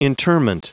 Prononciation du mot interment en anglais (fichier audio)
Prononciation du mot : interment